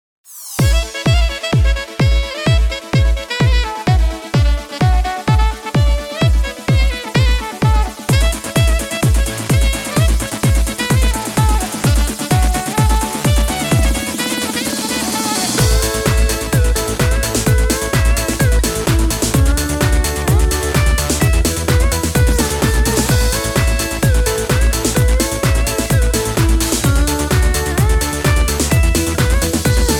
Patter